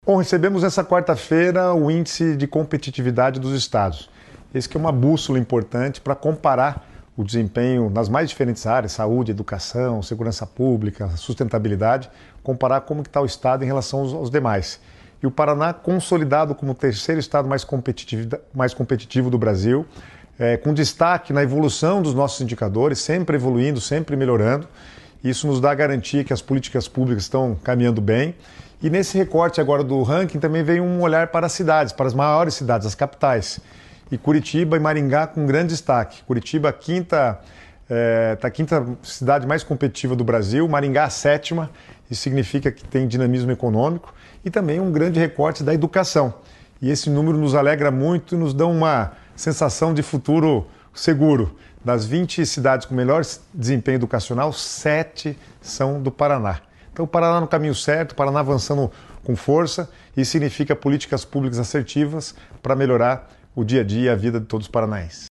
Sonora do secretário das Cidades, Guto Silva, sobre o Ranking de Competitividade dos Estados